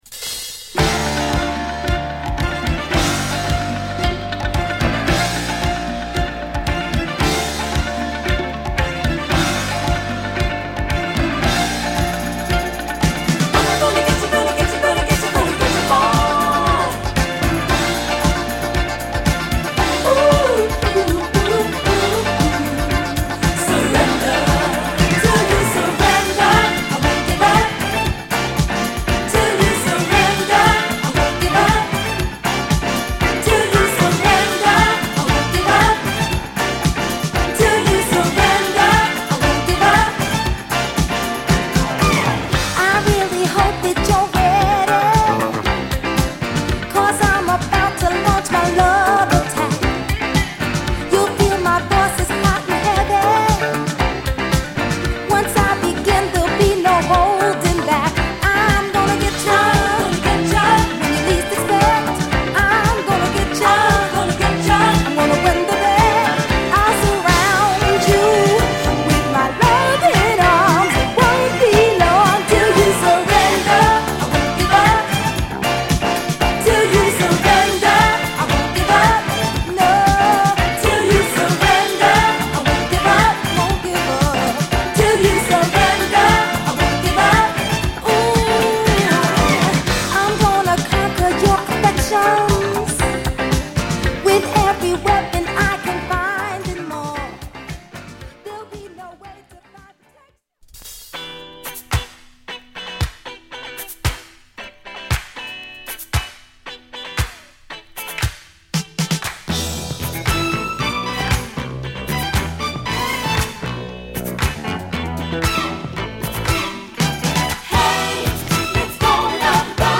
NYディスコ大名作